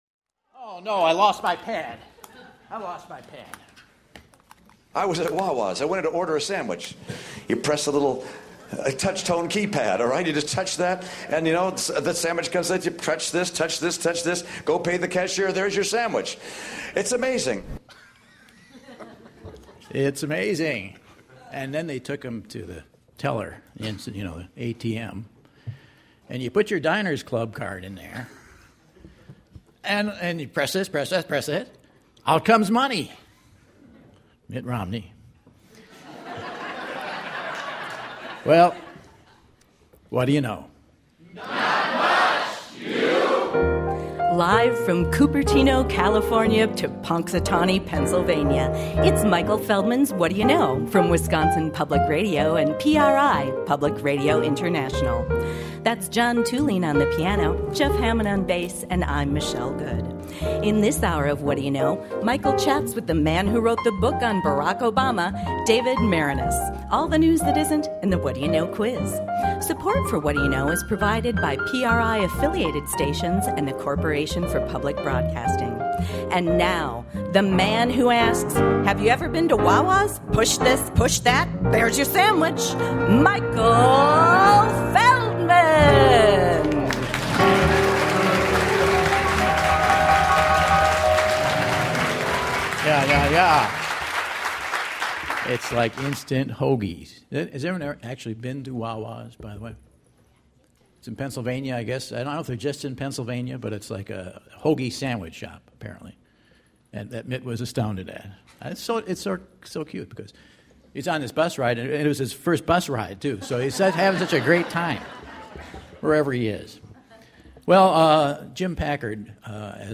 June 23, 2012 - Madison, WI - Monona Terrace | Whad'ya Know?